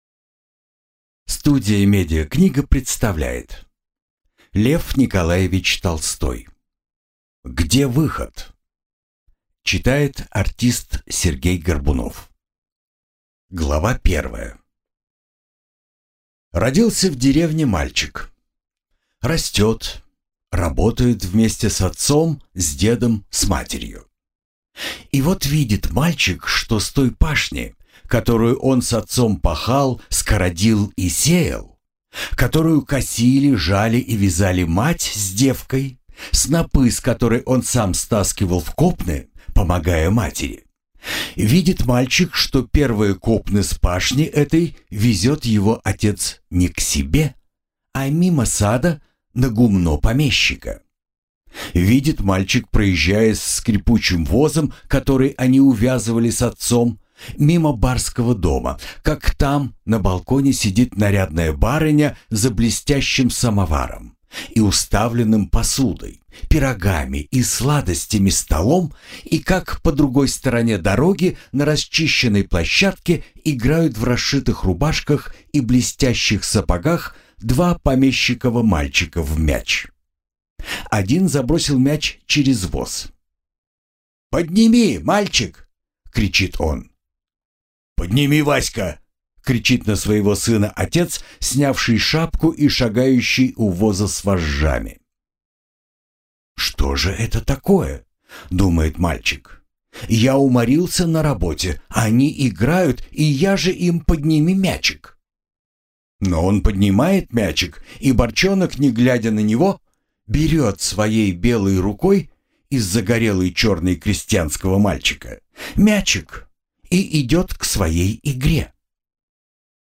Aудиокнига Где выход?